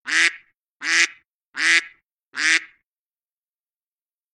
macOSsystemsounds
Duck.mp3